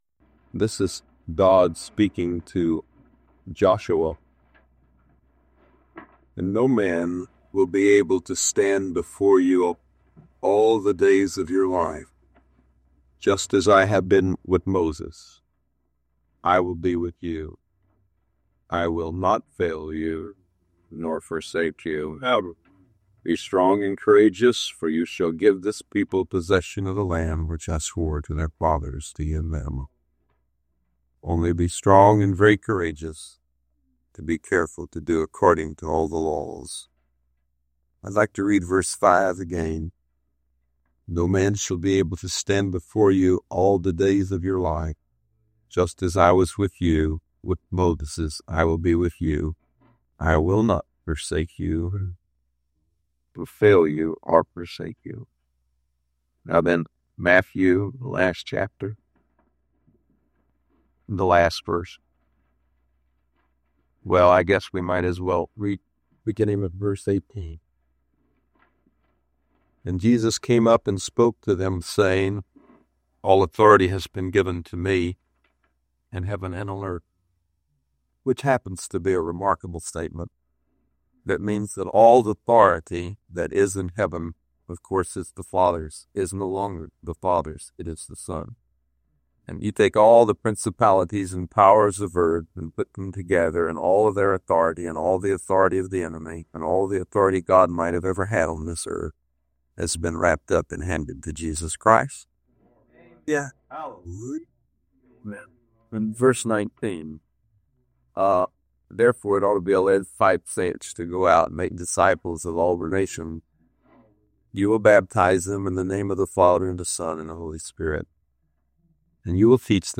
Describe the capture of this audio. Messages to the Church in Isla Vista, CA